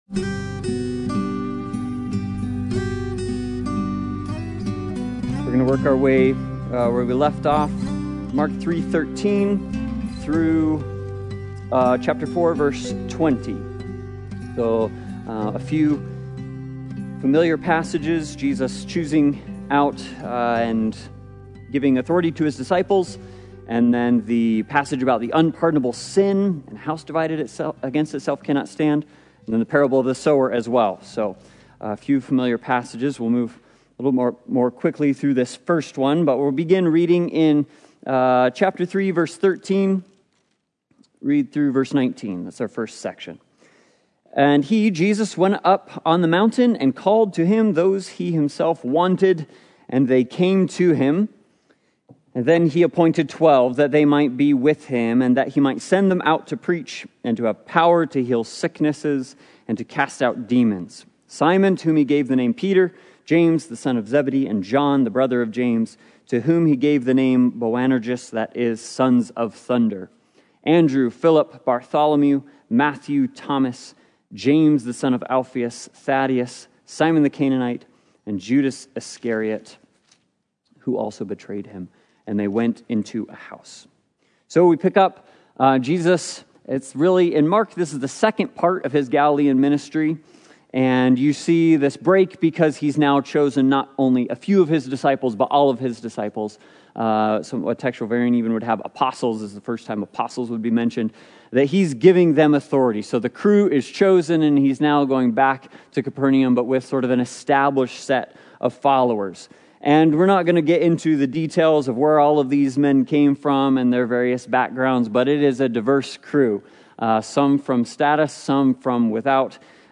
The Gospel According to Mark Service Type: Sunday Bible Study « Learning to Walk